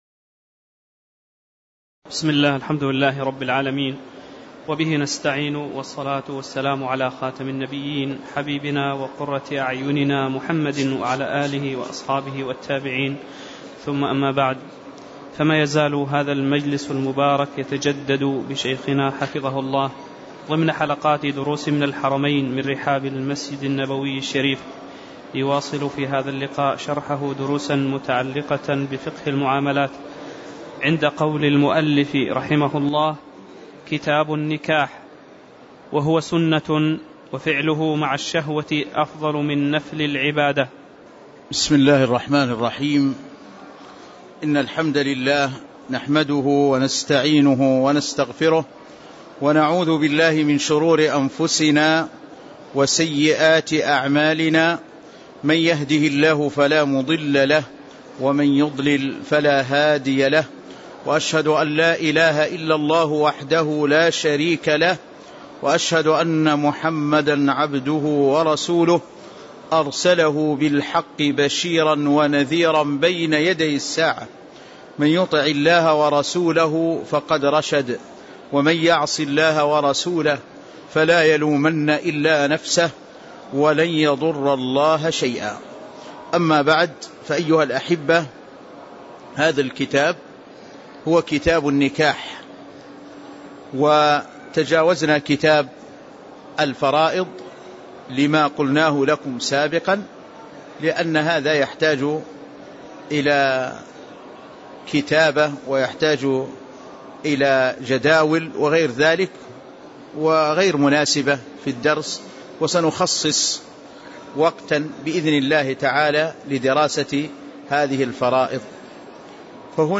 تاريخ النشر ٢٣ ربيع الثاني ١٤٣٧ هـ المكان: المسجد النبوي الشيخ